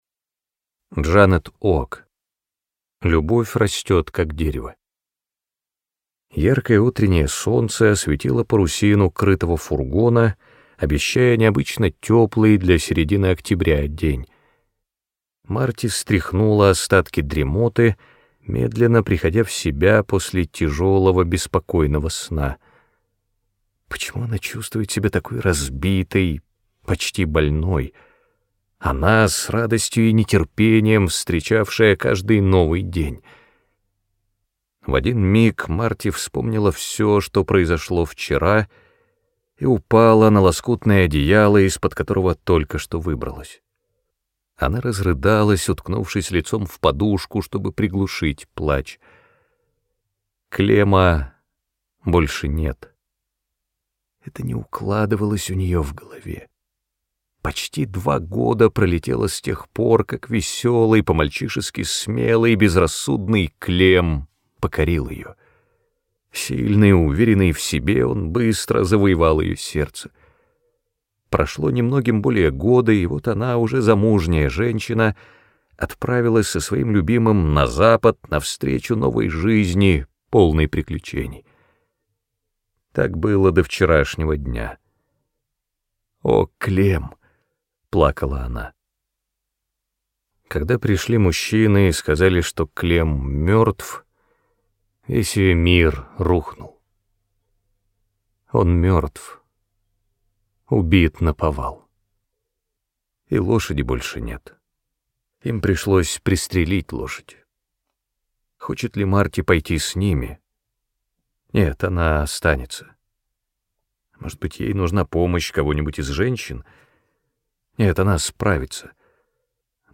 Аудиокнига Любовь растет, как дерево | Библиотека аудиокниг